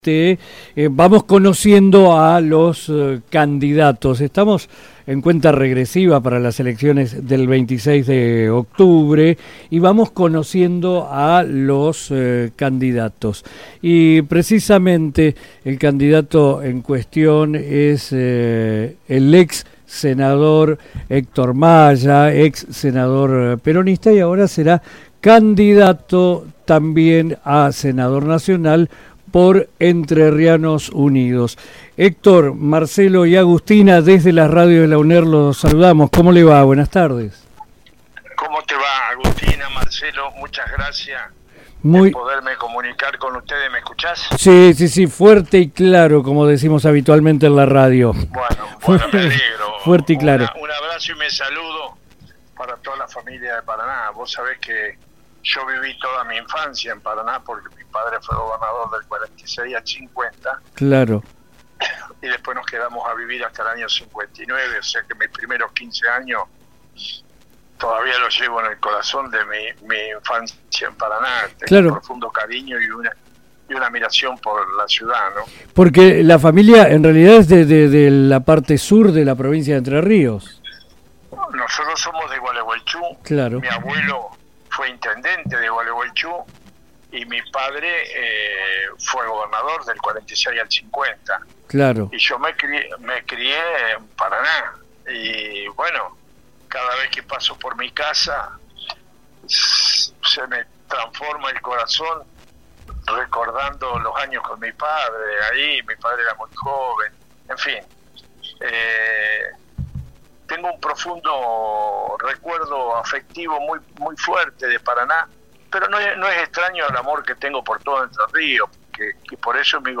Héctor Maya brindó una entrevista radial en el marco de su campaña a senador nacional.
Entrevista-Hector-Maya-08-10-1.mp3